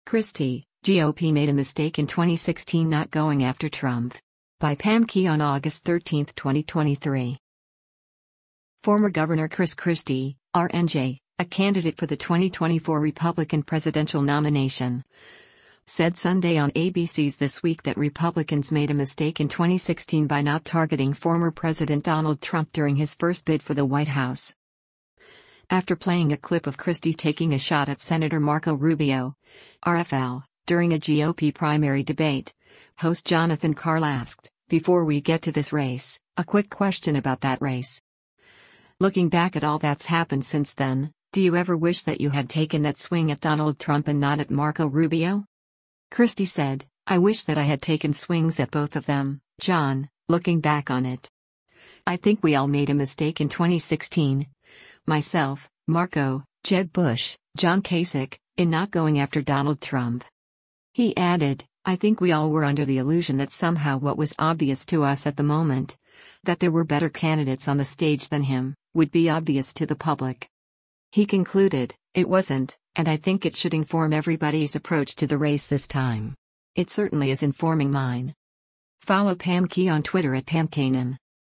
Former Gov. Chris Christie (R-NJ), a candidate for the 2024 Republican presidential nomination, said Sunday on ABC’s “This Week” that Republicans made “a mistake” in 2016 by not targeting former President Donald Trump during his first bid for the White House.
After playing a clip of Christie taking a shot at Sen. Marco Rubio (R-FL) during a GOP primary debate, host Jonathan Karl asked, “Before we get to this race, a quick question about that race. Looking back at all that’s happened since then, do you ever wish that you had taken that swing at Donald Trump and not at Marco Rubio?”